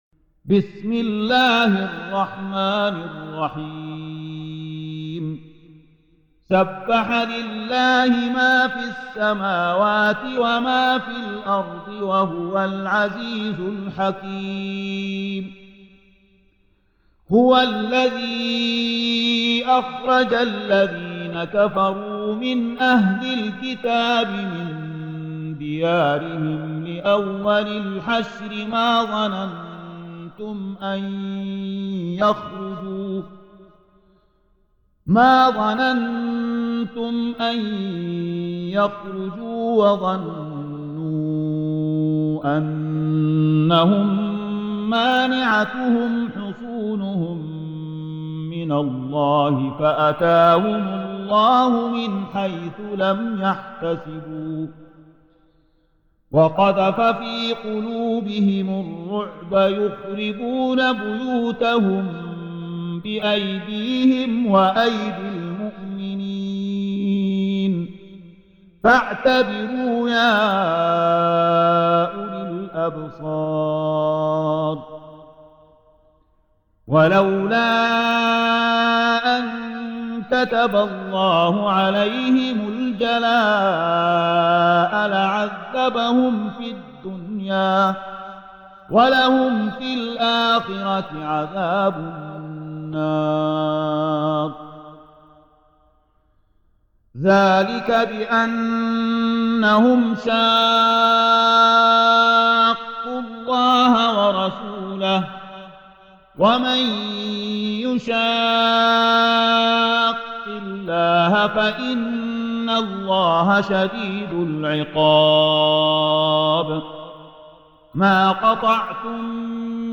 Surah Sequence تتابع السورة Download Surah حمّل السورة Reciting Murattalah Audio for 59. Surah Al-Hashr سورة الحشر N.B *Surah Includes Al-Basmalah Reciters Sequents تتابع التلاوات Reciters Repeats تكرار التلاوات